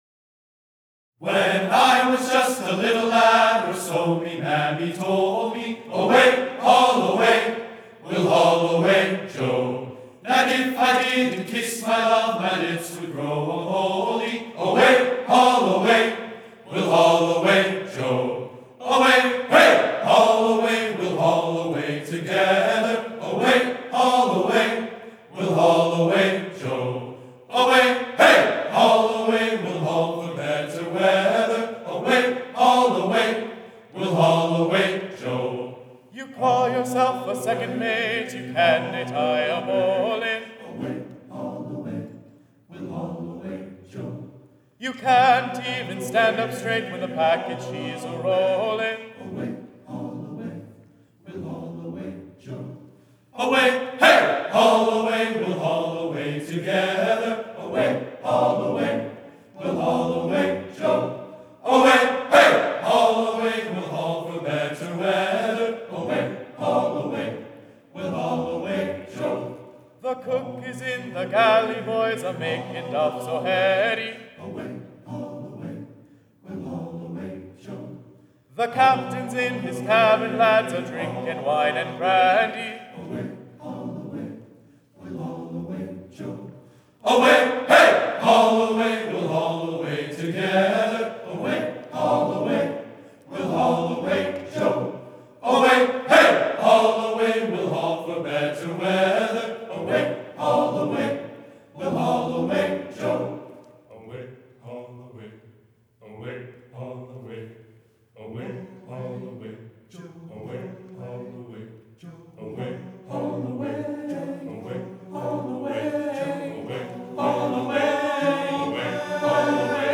Voicing: "TTBB"